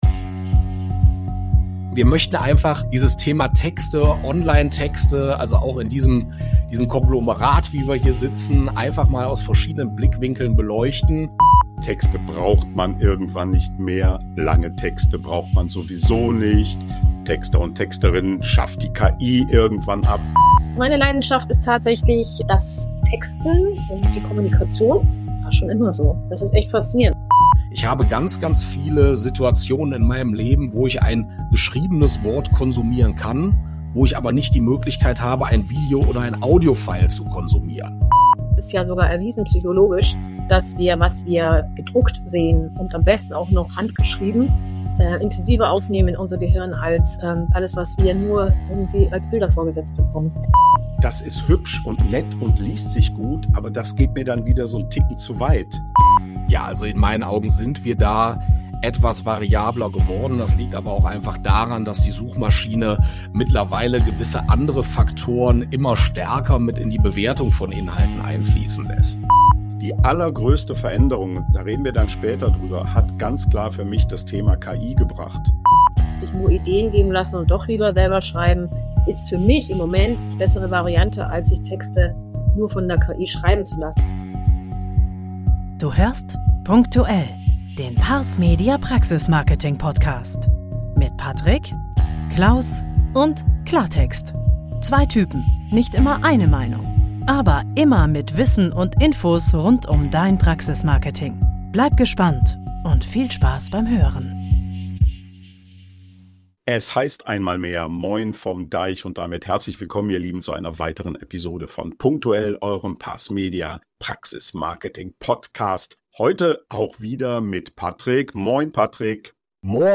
Onlinetexte – darum geht es: Talk